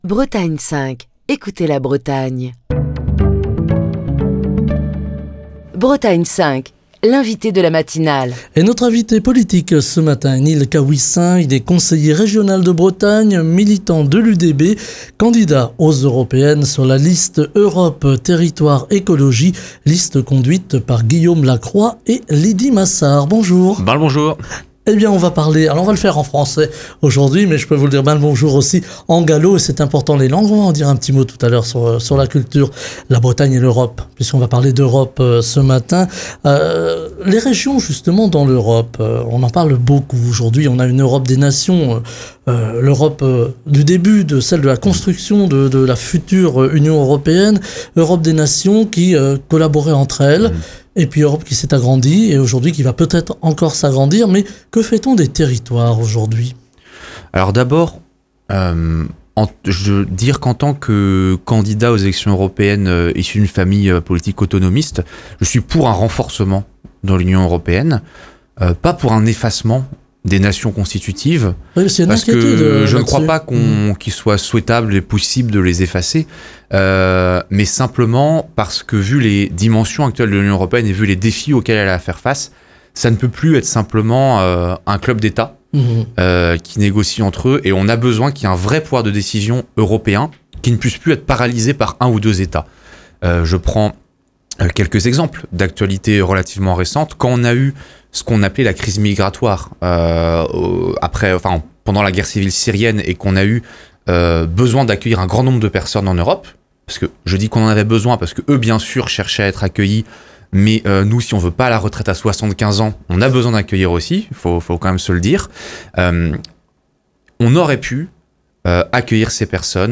Il évoque également la réforme attendue du fonctionnement de l'UE, et la nécessité de donner plus d'autonomie aux régions pour leur permettre de mettre en place des politiques spécifiques et de mieux répondre aux attentes des citoyens. Écouter Télécharger Partager le podcast Facebook Twitter Linkedin Mail L'invité de Bretagne 5 Matin